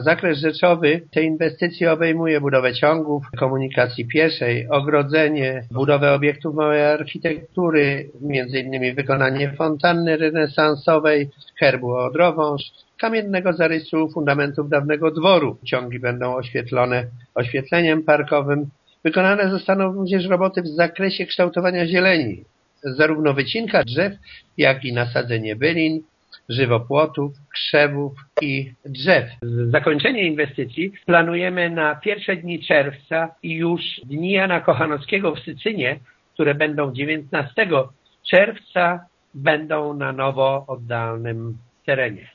Chcemy przywrócić dawny blask tego zabytkowego miejsca – mówi zastępca burmistrza Włodzimierz Kabus: